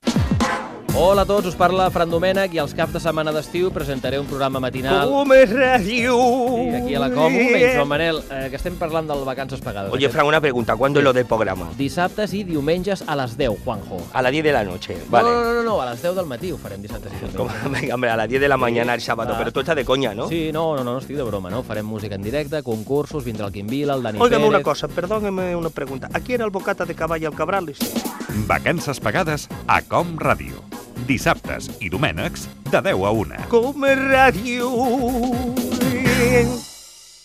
Promoció del programa d'estiu
Entreteniment